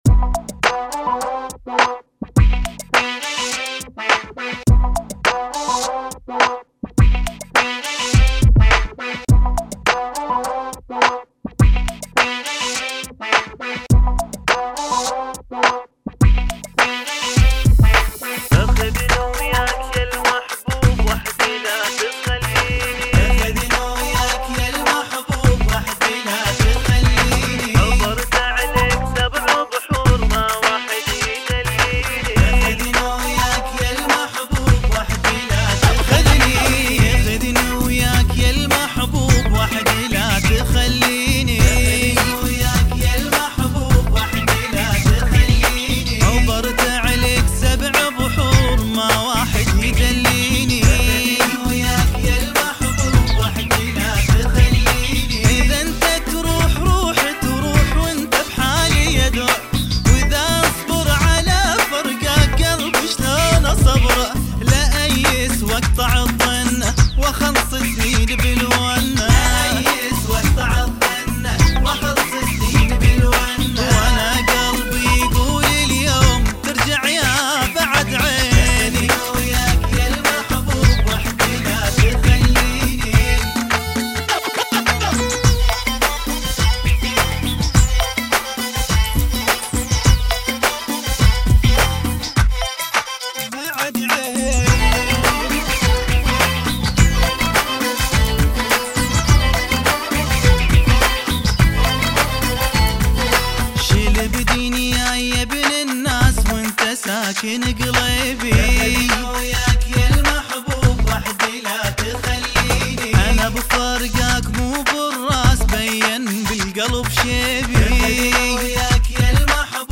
(104 BPM)